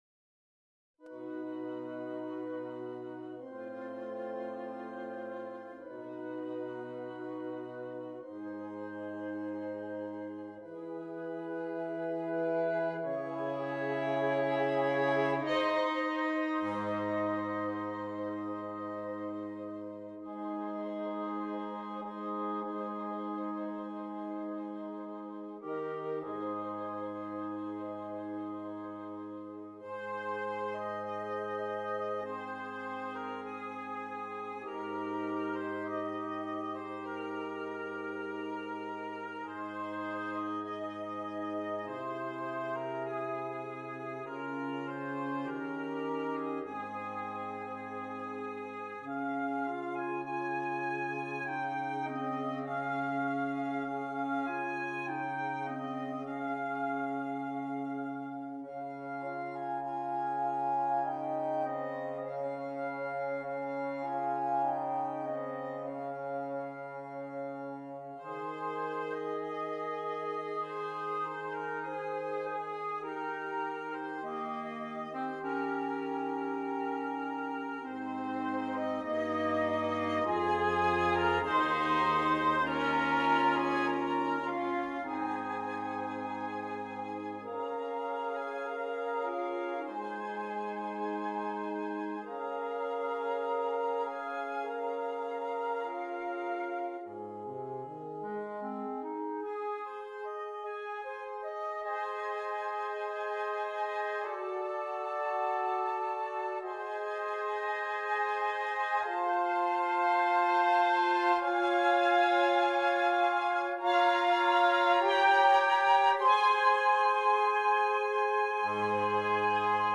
Woodwind Quintet
Difficulty: Easy-Medium Order Code